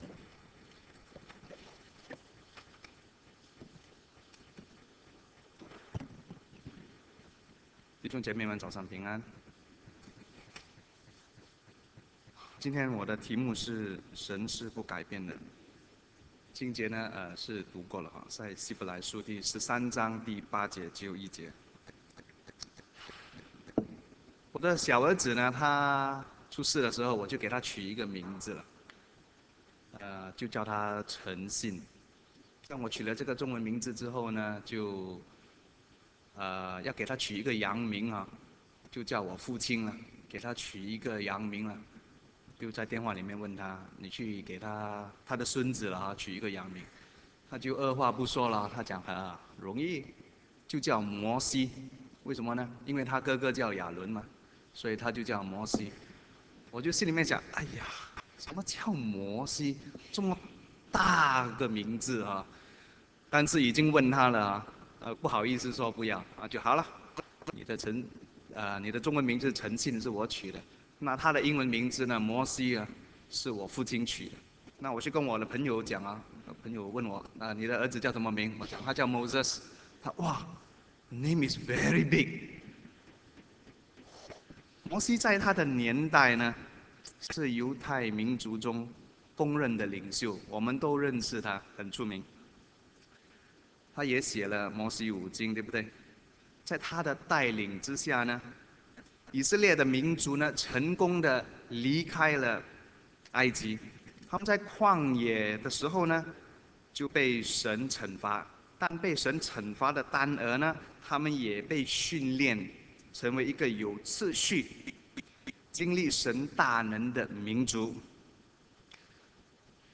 信息/Sermons (2012)